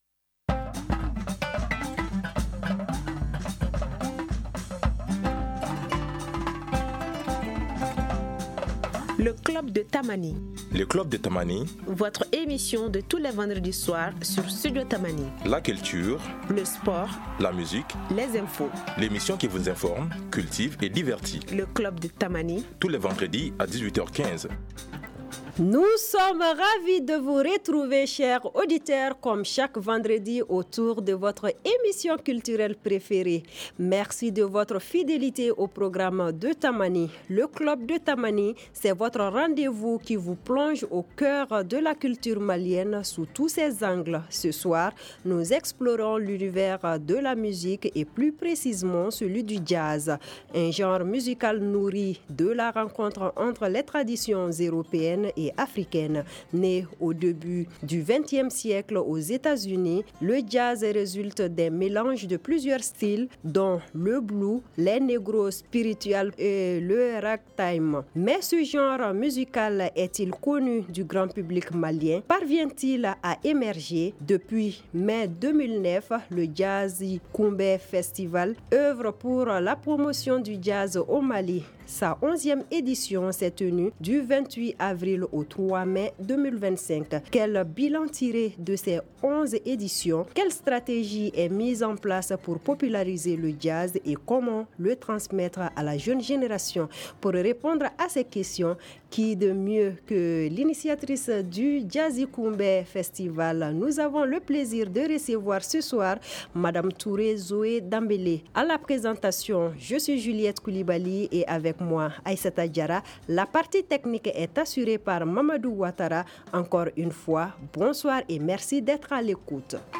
Notre invitée